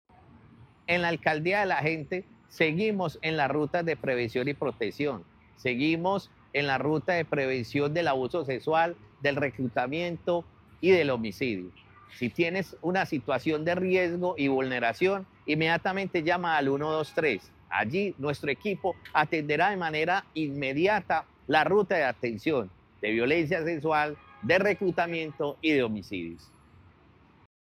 Declaraciones secretario de Paz y Derechos Humanos, Carlos Alberto Arcila A lo largo de 2025, la Alcaldía de Medellín continuó con atención integral a víctimas, protección de derechos y con acompañamiento cercano en distintos tipos de casos.
Declaraciones-secretario-de-Paz-y-Derechos-Humanos-Carlos-Alberto-Arcila.mp3